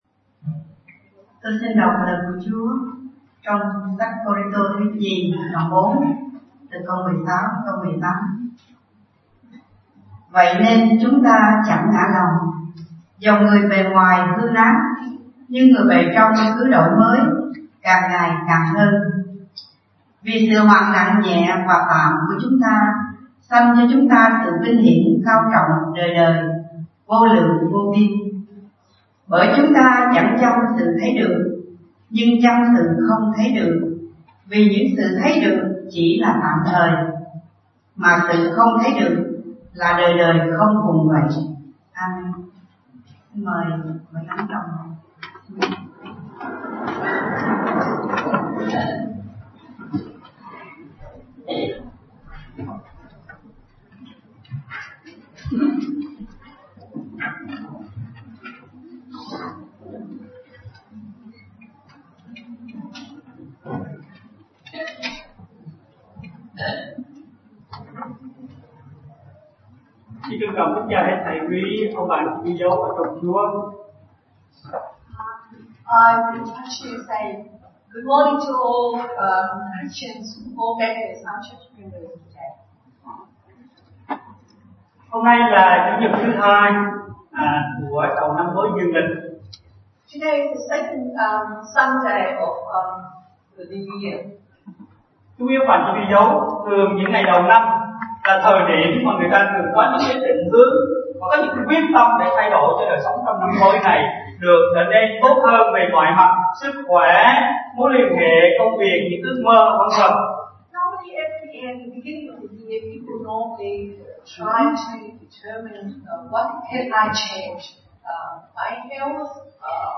Bài Giảng